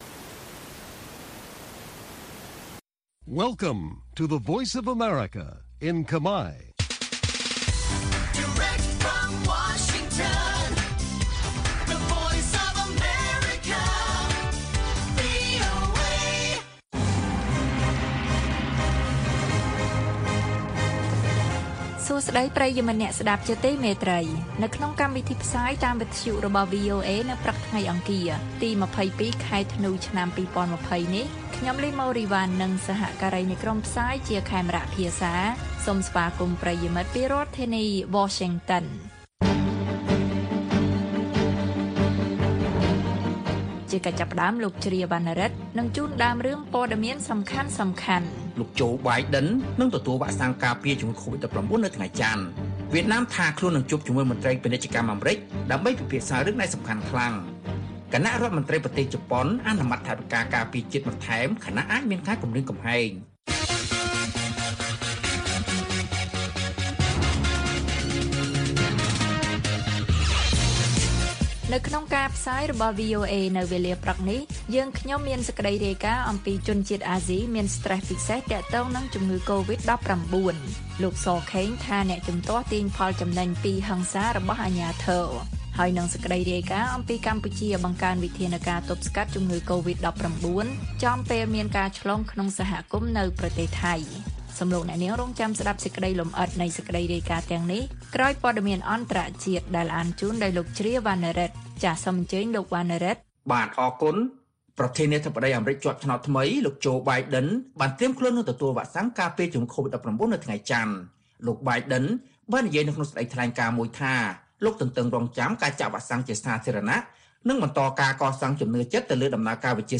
ព័ត៌មានពេលព្រឹក៖ ២២ ធ្នូ ២០២០